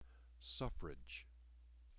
This plays the word pronounced out loud.